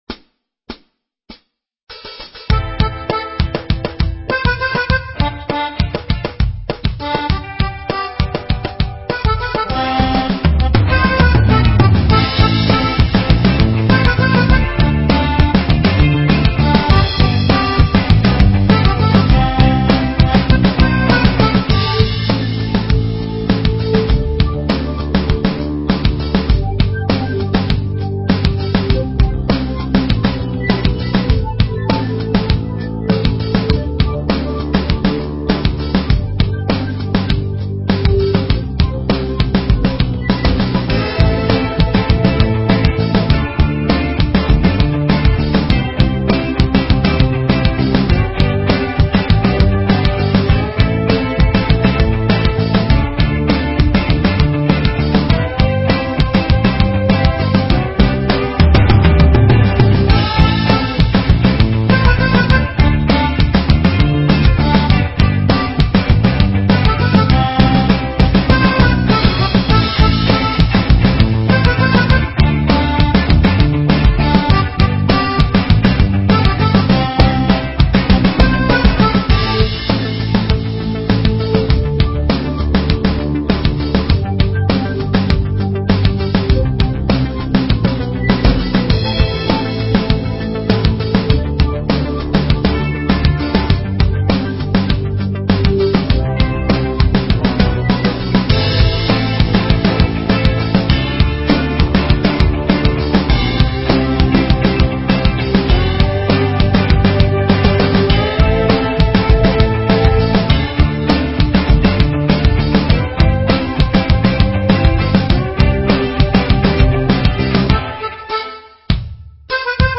Минус для будущей песенки